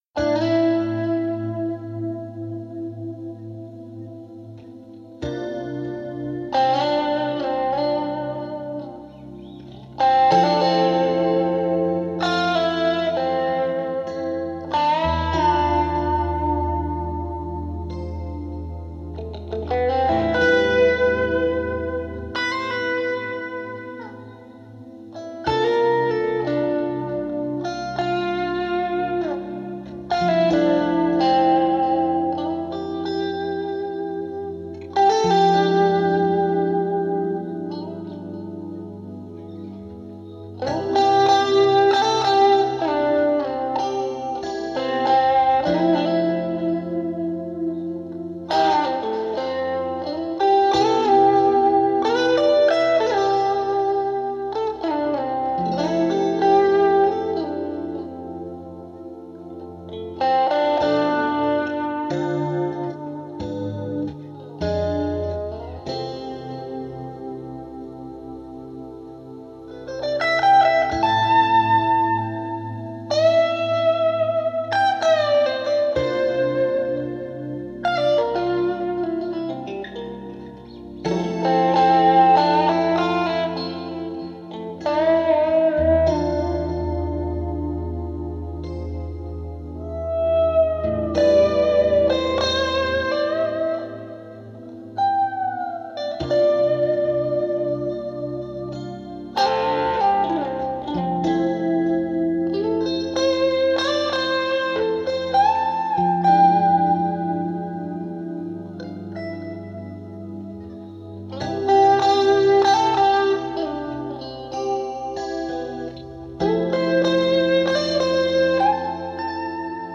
[Artist: Instrumental ]